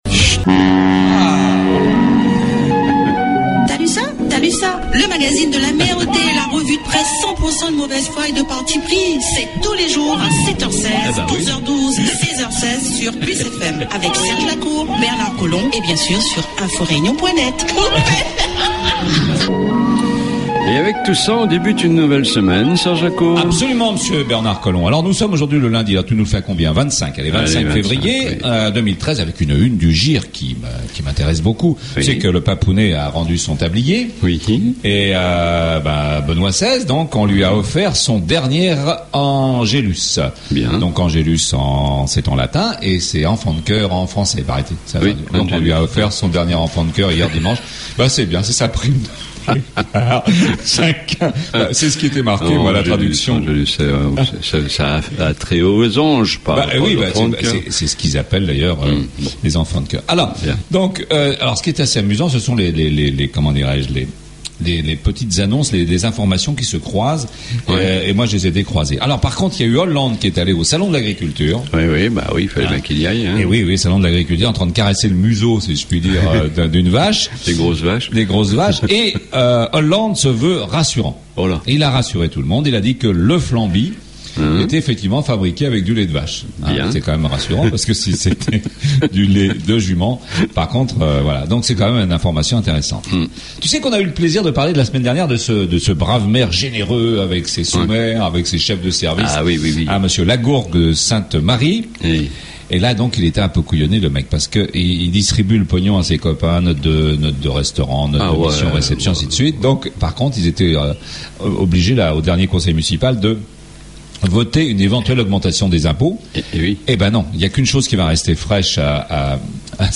REVUE DE PRESSE